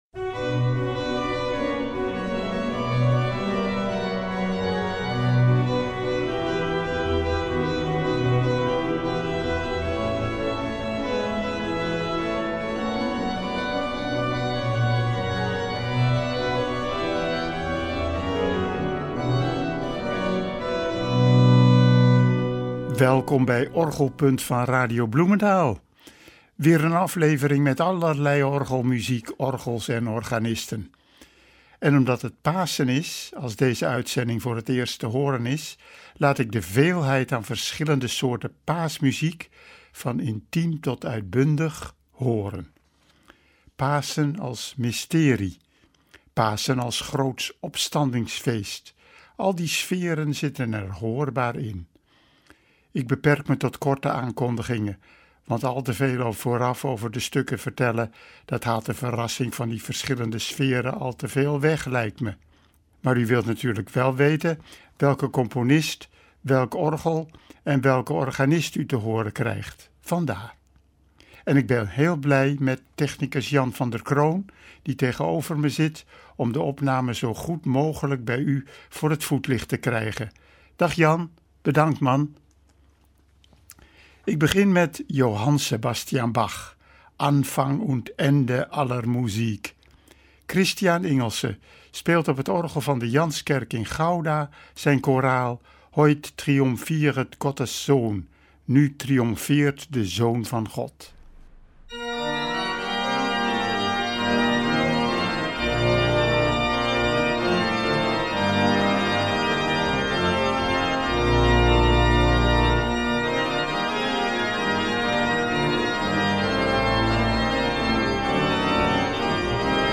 Vandaag weer een aflevering met allerlei orgelmuziek, orgels en organisten. En omdat het Pasen is als deze uitzending voor het eerst te horen is, laat ik een veelheid aan verschillende soorten paasmuziek, van intiem tot uitbundig horen. Pasen als mysterie, Pasen als groots opstandingsfeest, al die sferen zitten er hoorbaar in.
Van mysterieus naar uitbundig in 2 minuten.